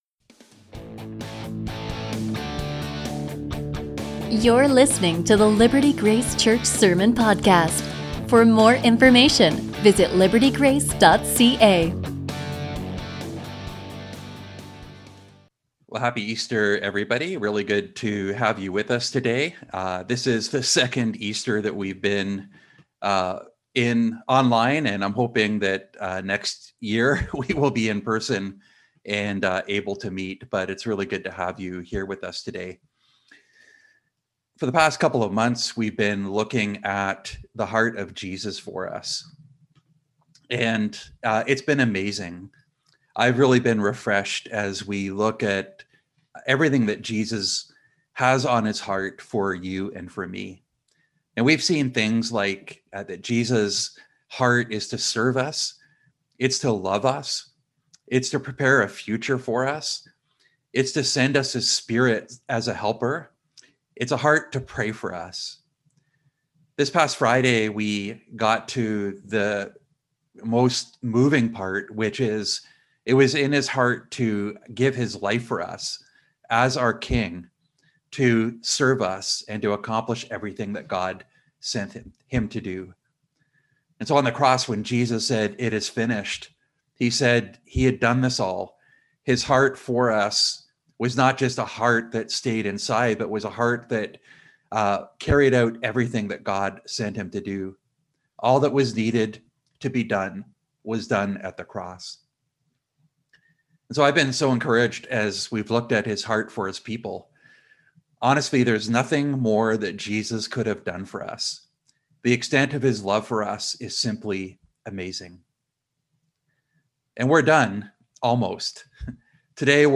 A sermon from John 20:19-23